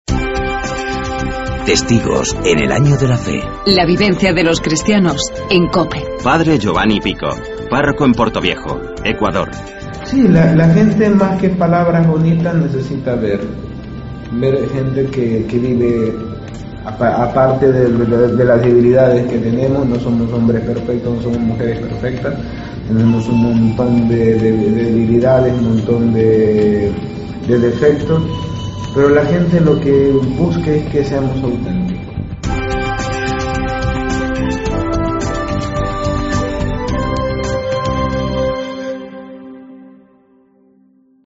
AUDIO: Escuchamos el testimonio